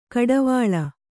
♪ kaḍavāḷa